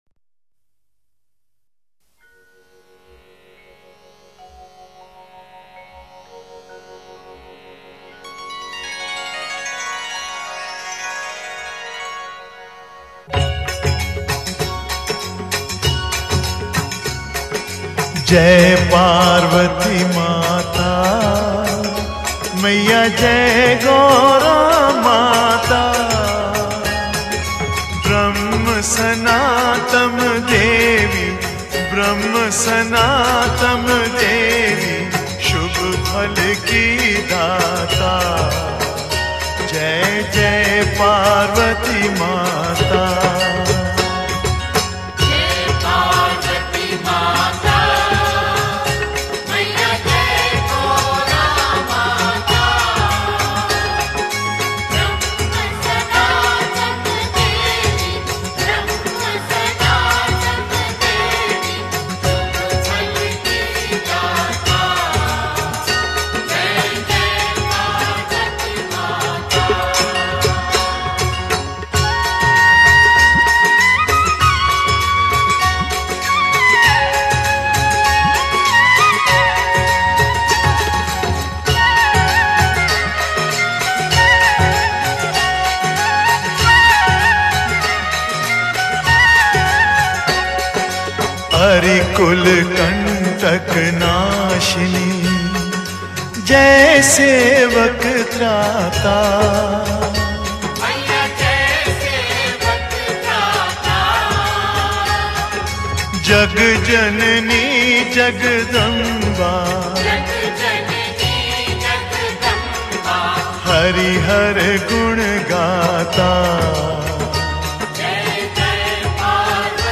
Devotional Single Songs - Bhajans